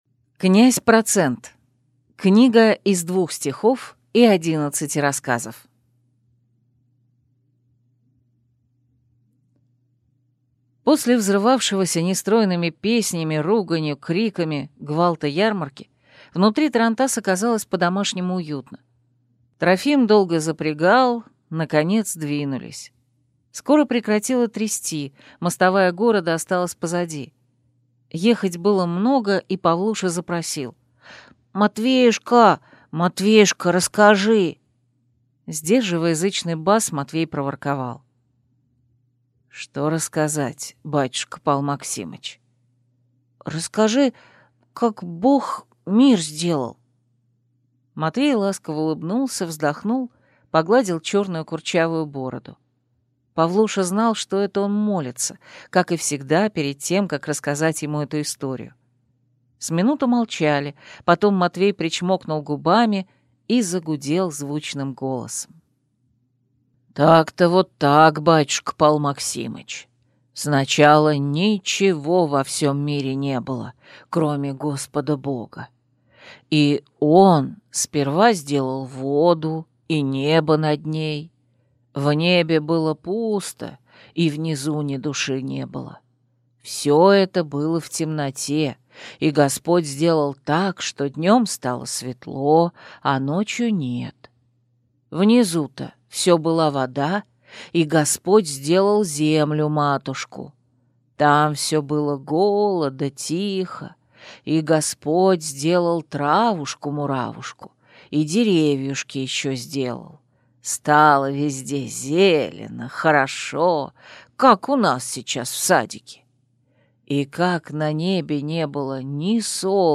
Аудиокнига Книга из двух стихов и одиннадцати рассказов | Библиотека аудиокниг